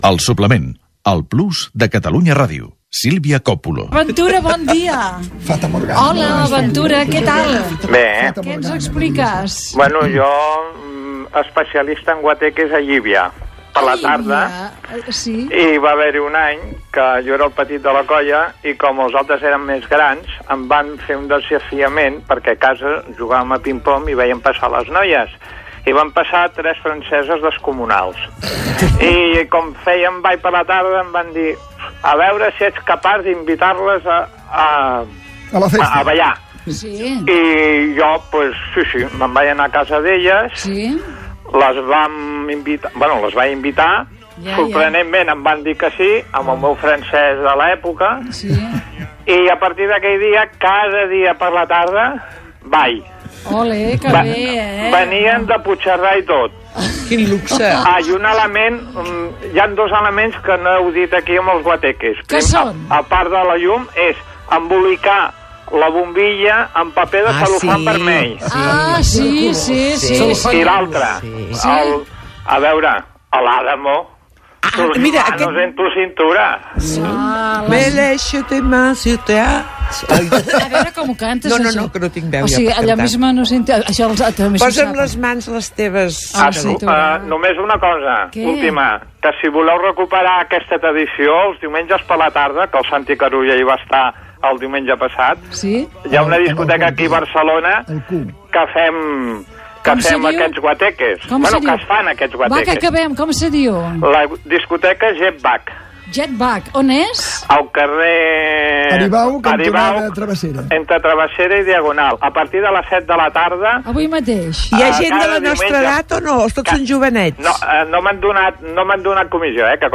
Un oient recorda les festes a les discoteques, explicant el cas de Llívia.
Entreteniment